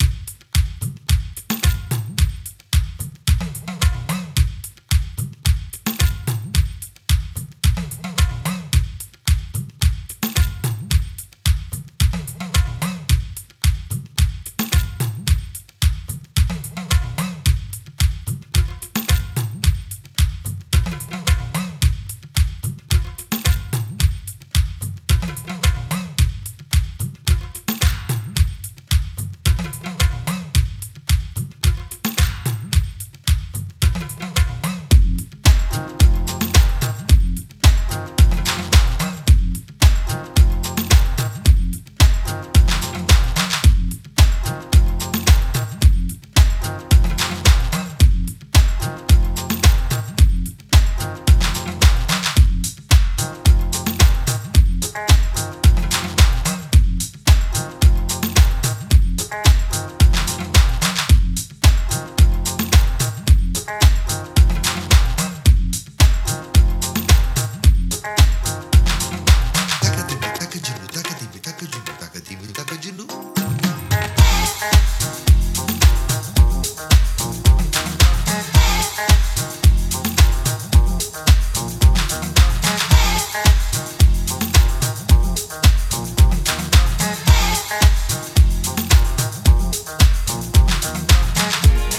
70年代イタリア産ファンク/ディスコ音源をファットに調理した、オブスキュア&パワー型エディットを仕上げてきました。